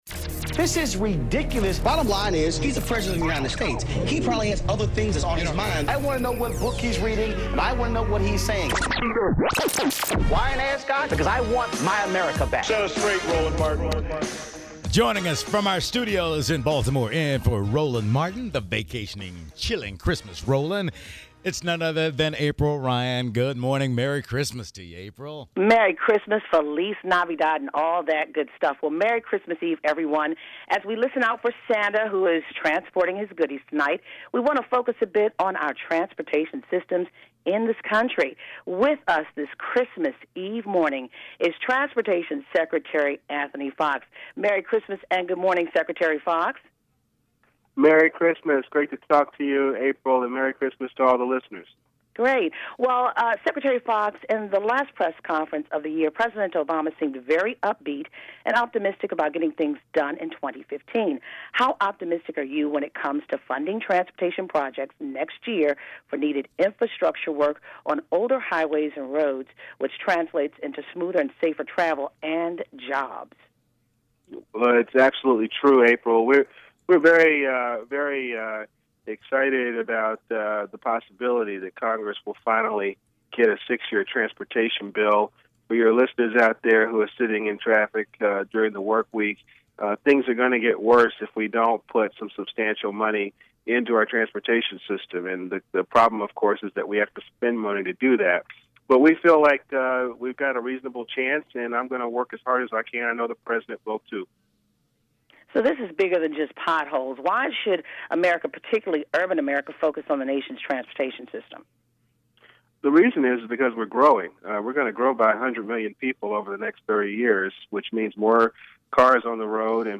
April Ryan talks to Transportation Secretary Anthony Fox about the funding transportation work that needs to happen in 2015, to improve commuter time, potholes and public transit.